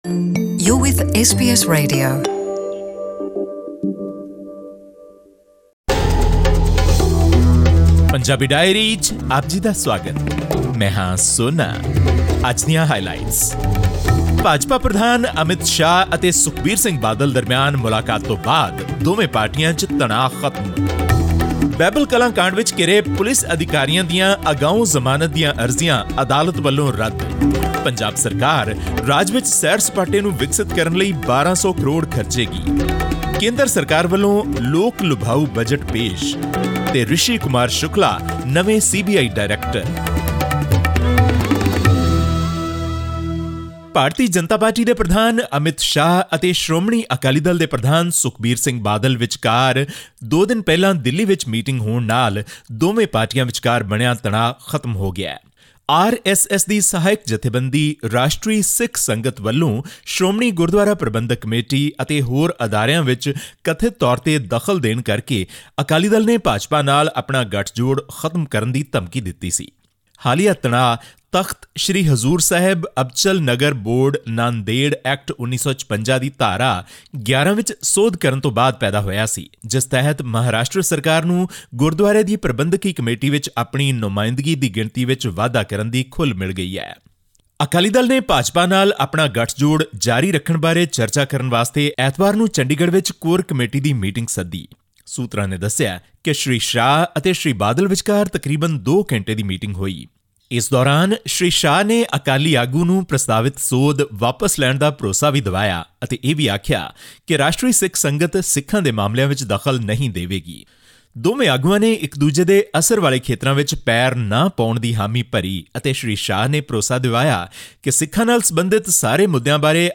Our India correspondent brings you a wrap of the most important news stories from Punjab.